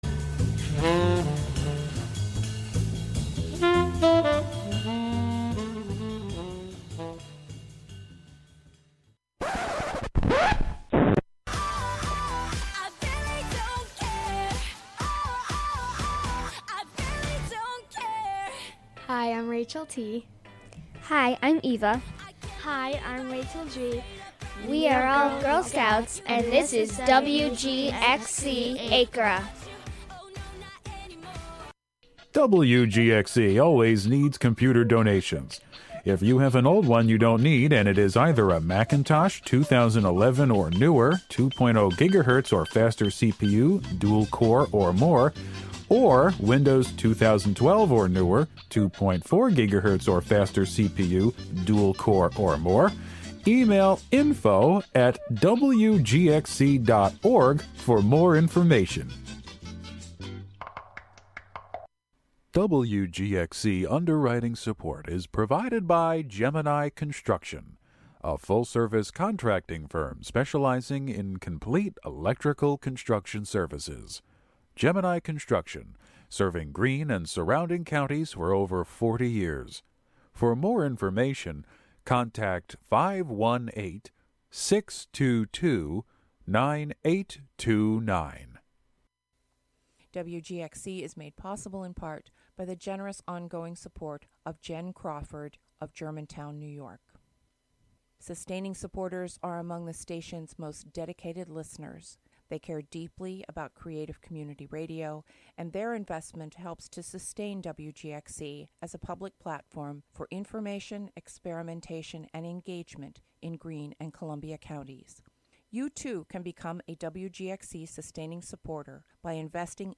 An eclectic sonic tour of futuristic soul, cosmic-leaning roll, lost classics, body music, disco-punk, and more. Live from WGXC's Acra Studio.